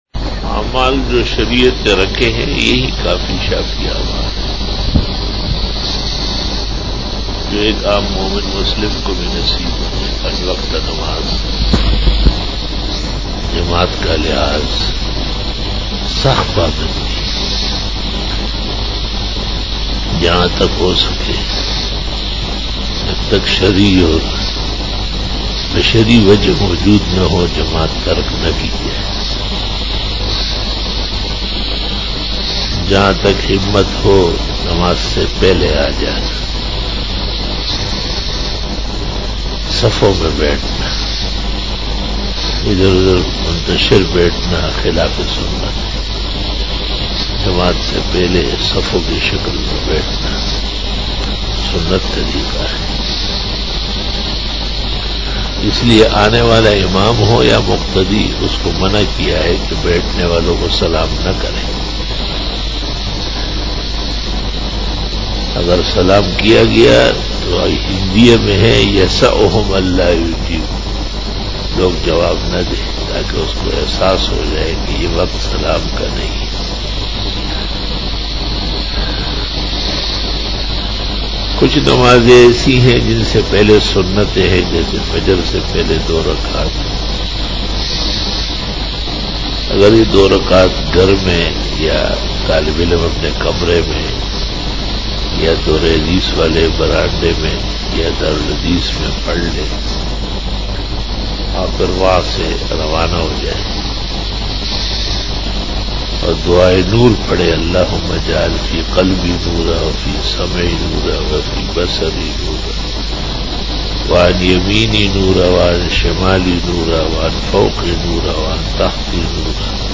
بیان بعد نماز فجر بروز پیر 24 جمادی الاول 1441ھ/ 20 جنوری 2020ء"